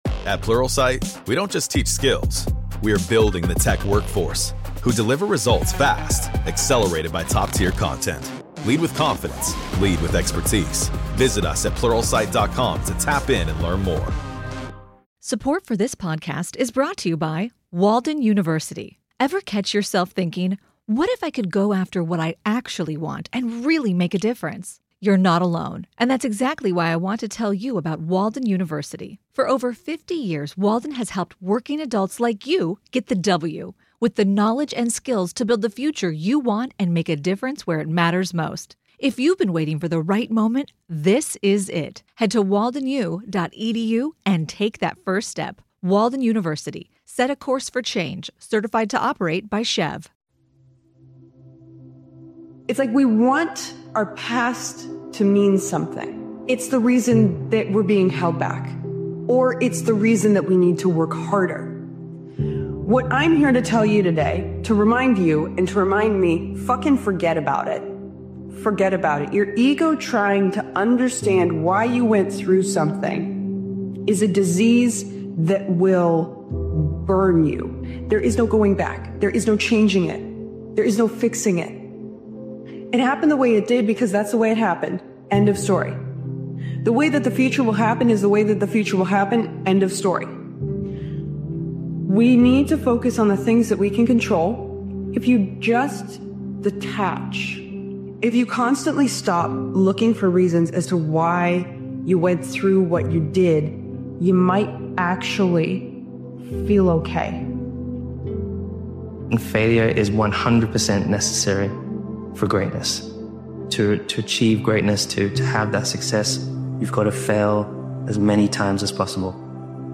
Powerful Motivational Speech Video is a fearless and emotionally charged motivational episode created and edited by Daily Motivations. This powerful motivational speeches compilation captures the energy of redemption and resilience—rising from setbacks stronger, sharper, and more focused than ever.